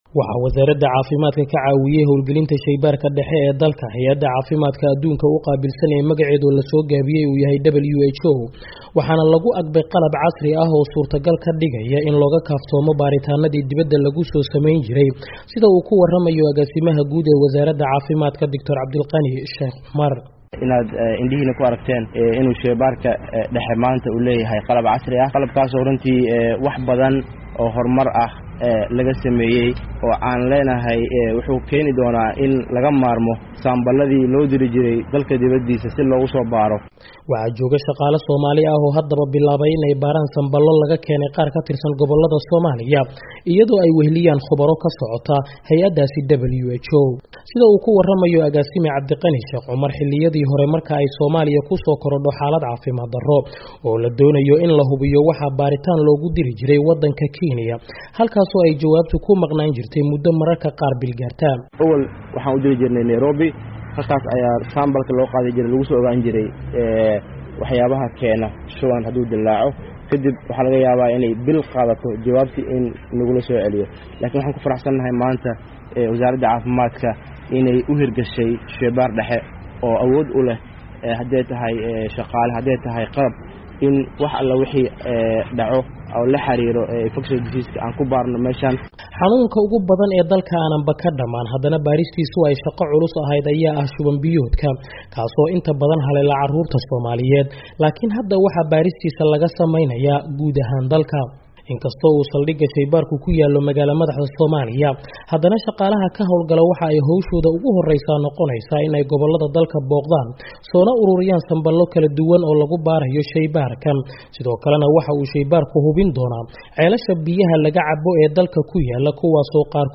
Warbixin: Sheybaarka Dhexe